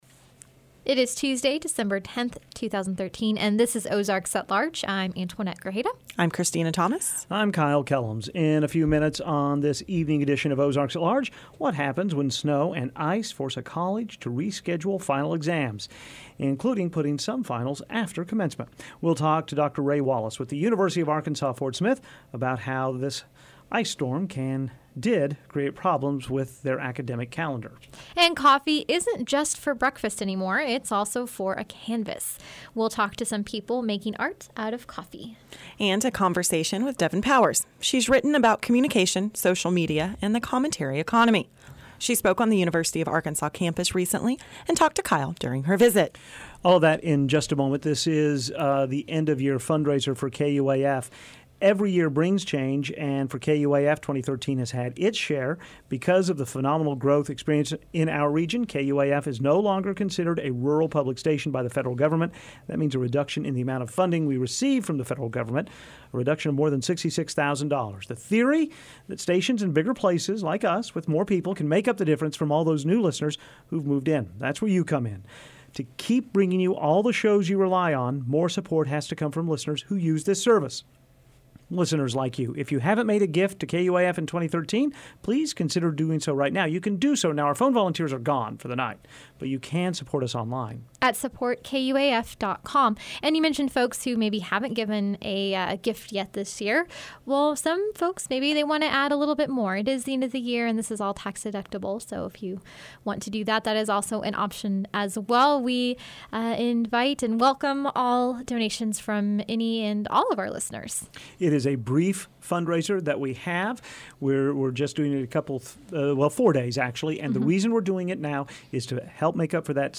And coffee isn't just for breakfast anymore…it's also for a canvas. We'll talk to some people making art out of coffee.